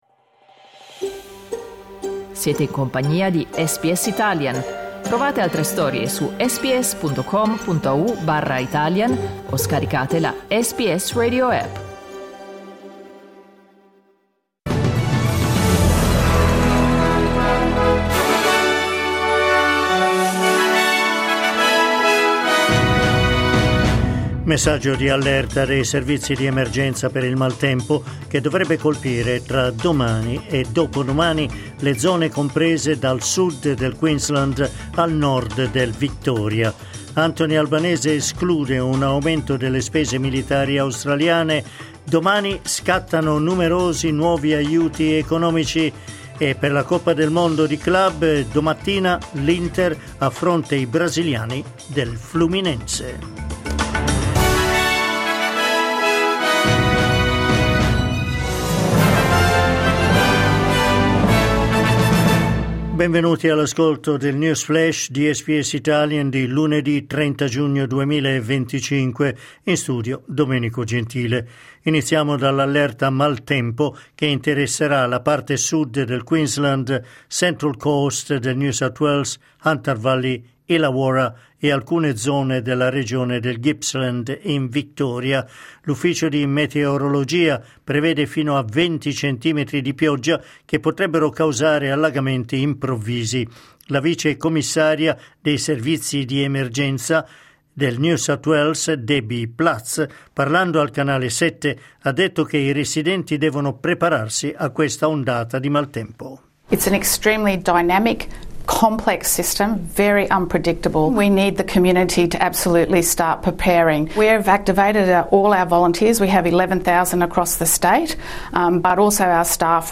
News flash lunedì 30 giugno 2025